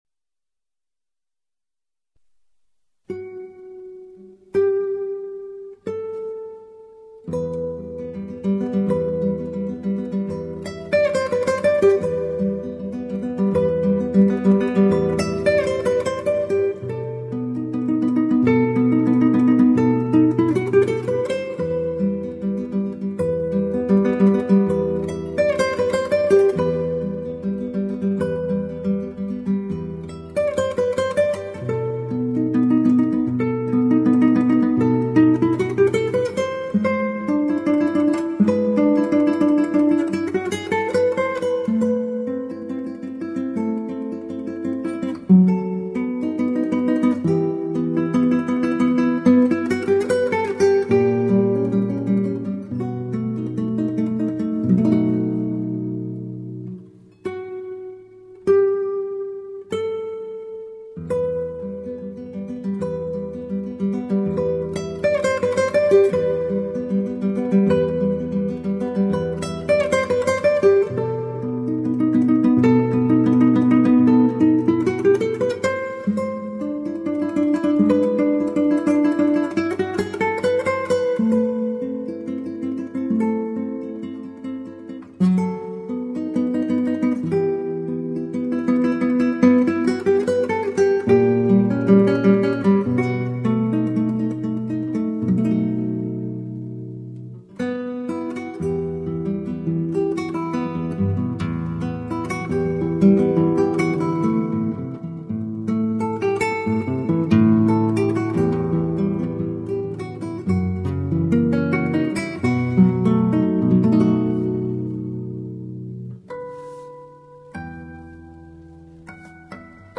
0241-吉他名曲斯凯探戈舞曲.mp3